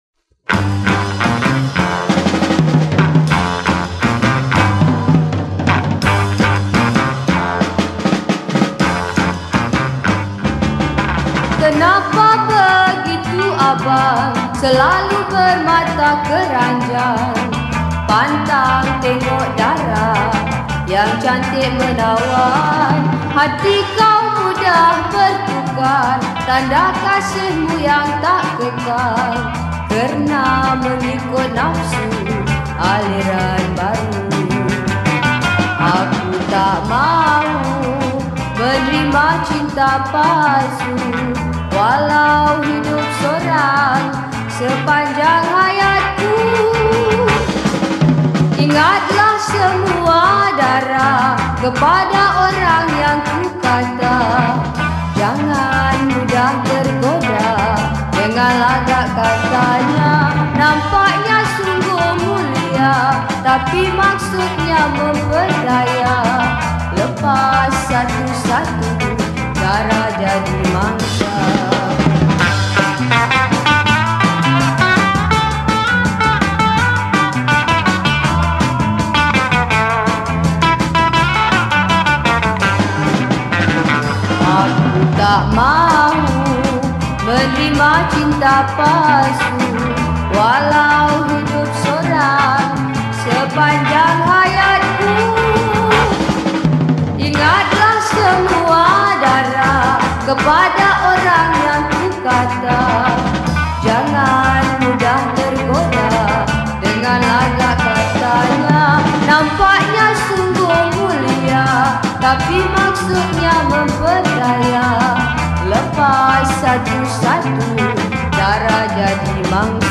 Malay Songs , Pop Yeh Yeh